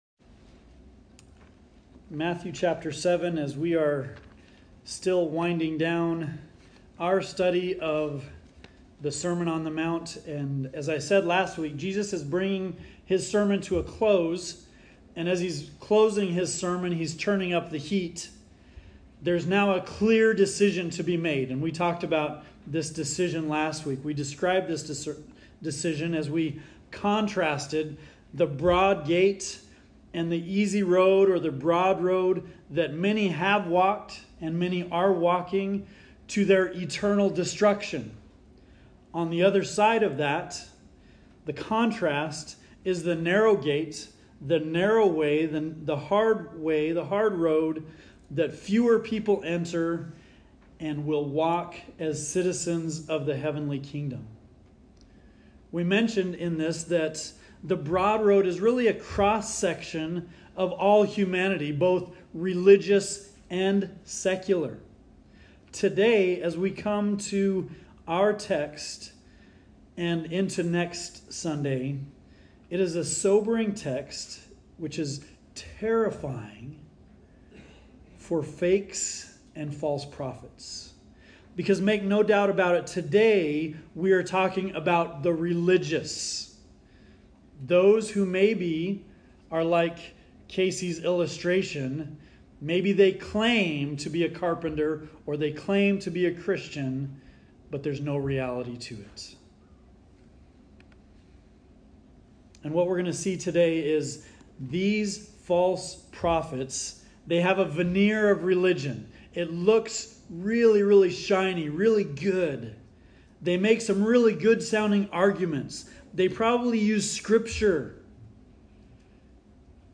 False Teachers and Bad Trees – Kadoka, Okaton, and Belvidere Churches